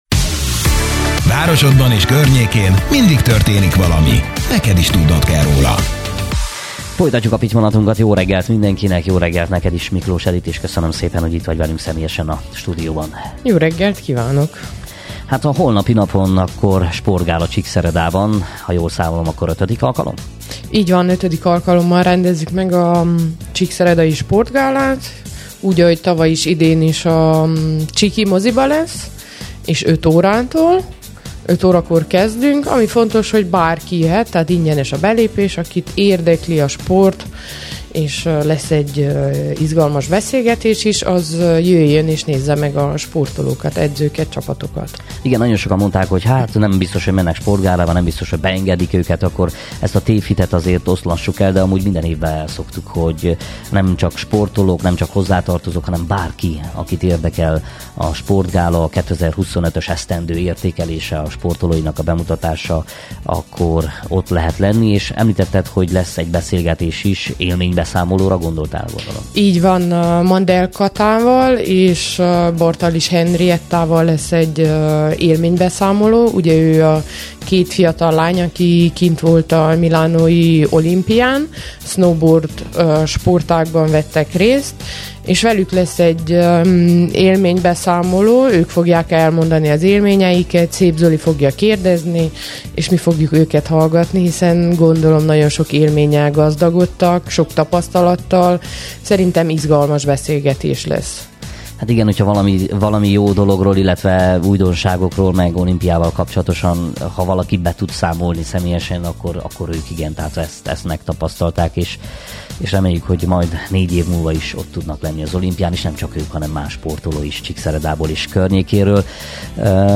Pitymallat-Az Év Sportolója Gála2025(Miklós Edit volt a vendégünk) - Szépvíz FM